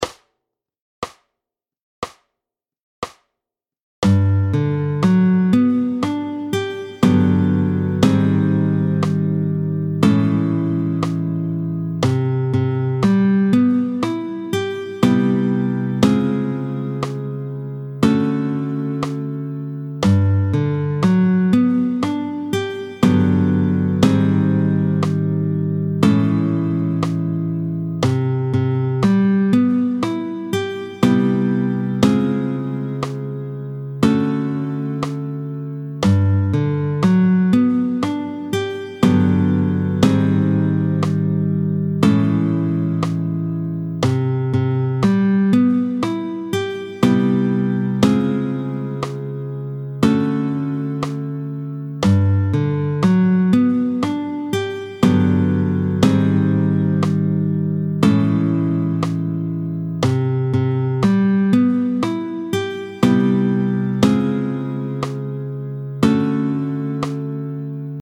28-06 Les accords de 7ème majeure, tempo 60